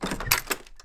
door-open-1.mp3